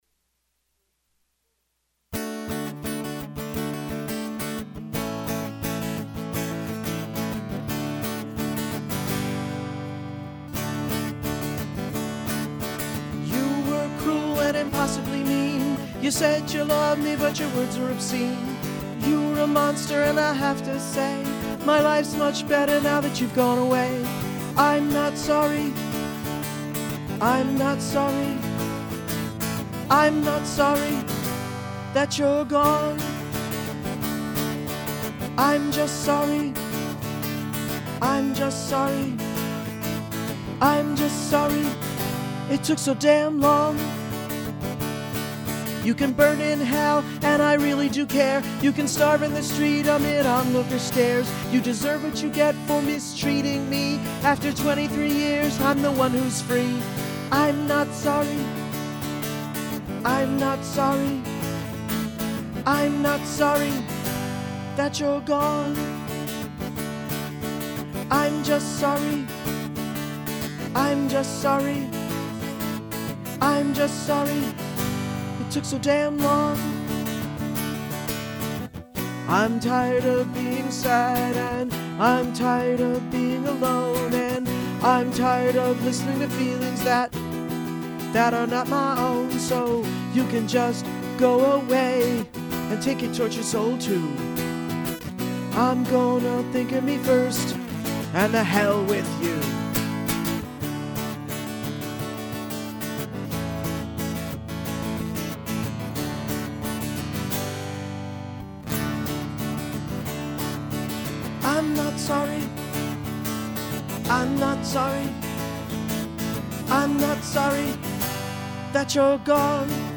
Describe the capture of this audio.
Recorded in Low-Fidelity December 2005-February 2006 at